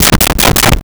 Knocking On Door And In A Hurry
Knocking on Door and in a Hurry.wav